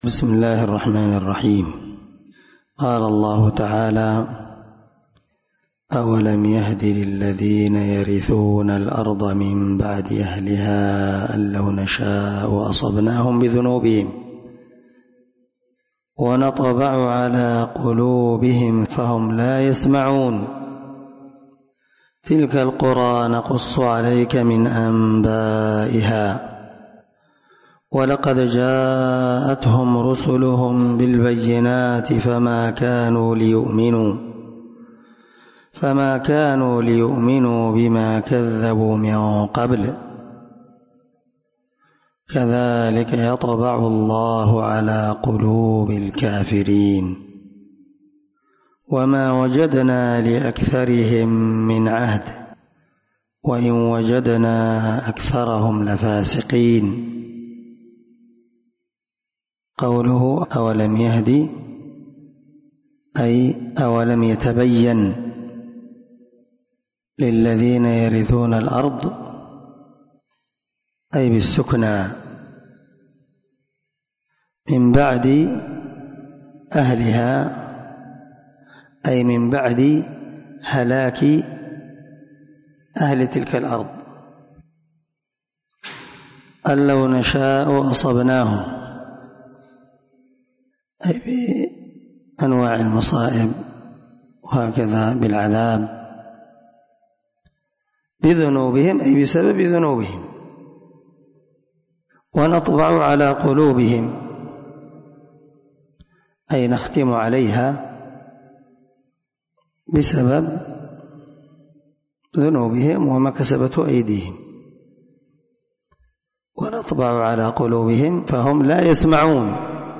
478الدرس 30 تفسير آية ( 100 – 102 ) من سورة الأعراف من تفسير القران الكريم مع قراءة لتفسير السعدي